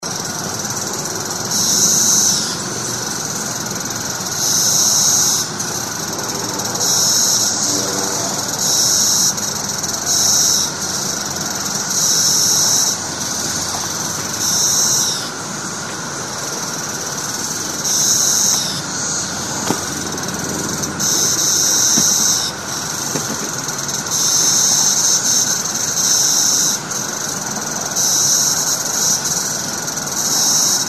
These are 13-year cicadas and they are loud and proud.
Here’s a few seconds of sound I recorded just now with my iPhone from the same tree I shot the photo. You can also hear a nearby highway.
Cicada Sound
Now, that will either put you to sleep while you lie in your hammock or else it will grate on your nerves and force you to put your ear buds on and crank up your favorite Pandora station.
cicada-sound.mp3